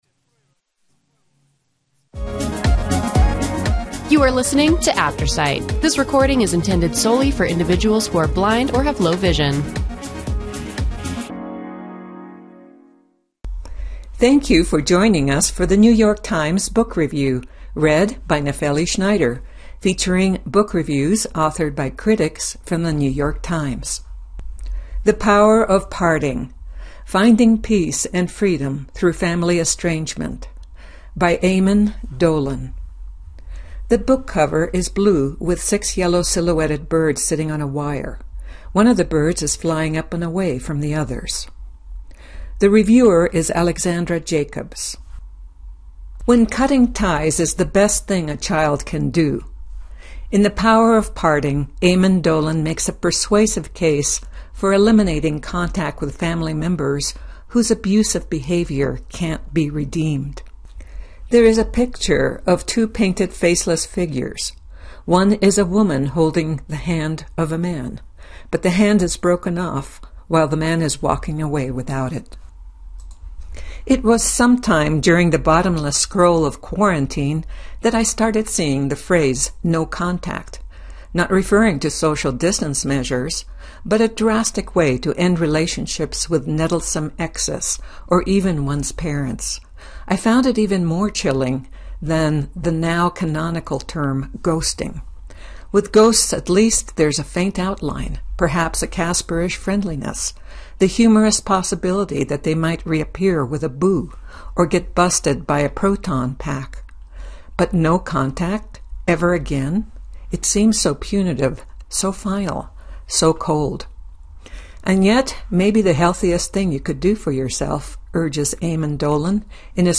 Audio formatted version of the New York Times Book Review, weekly. Aftersight is a media organization that serves individuals with barriers to print.